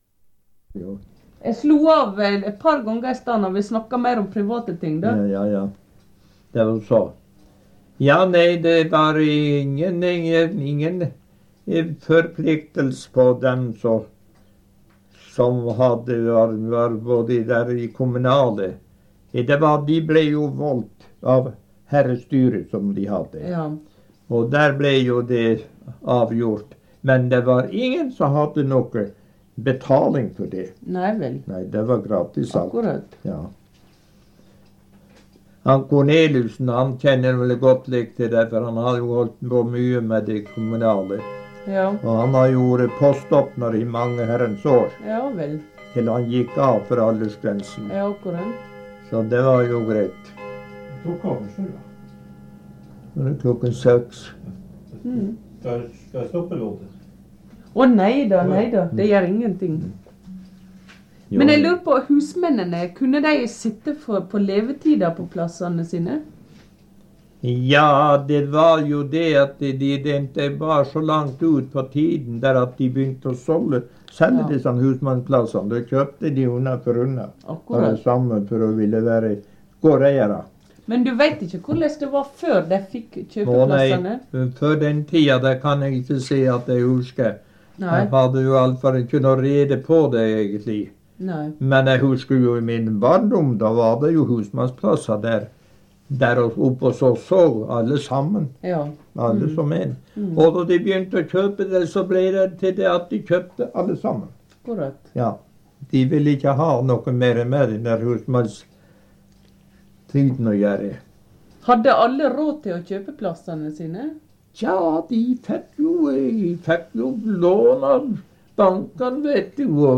Sted: Skånland, Evenskjer